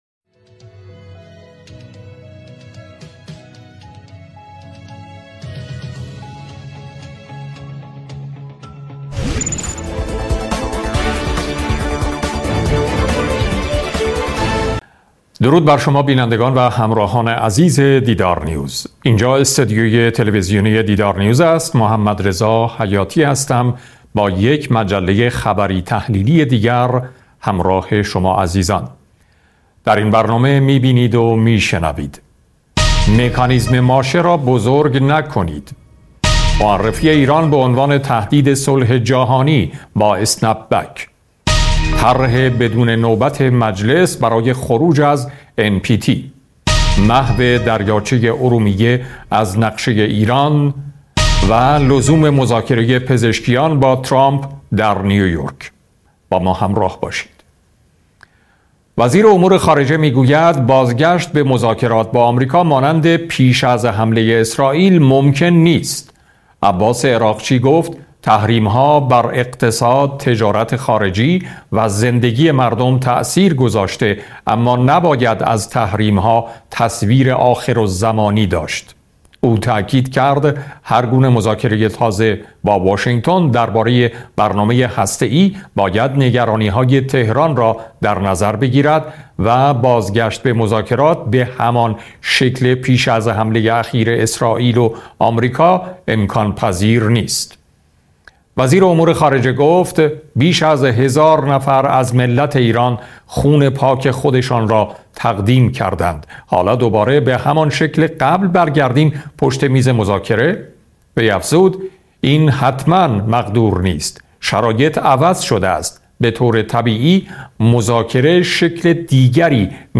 صدای مجله خبری 17 شهریور